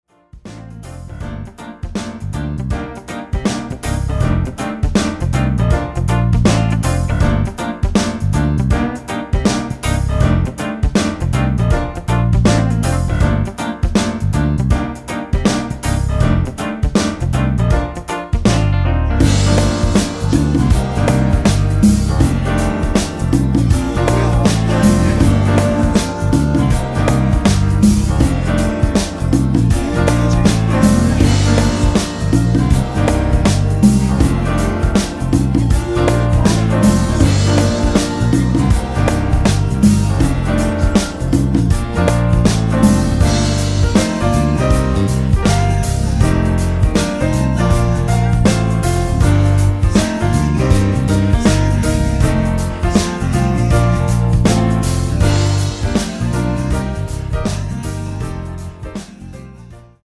코러스 포함된 MR 입니다.(미리듣기 참조)
엔딩이 페이드 아웃이라 라이브 하시기 좋게 엔딩을 만들어 놓았습니다.(코러스 없는 MR 미리듣기 참조)
Gm
앞부분30초, 뒷부분30초씩 편집해서 올려 드리고 있습니다.
중간에 음이 끈어지고 다시 나오는 이유는
곡명 옆 (-1)은 반음 내림, (+1)은 반음 올림 입니다.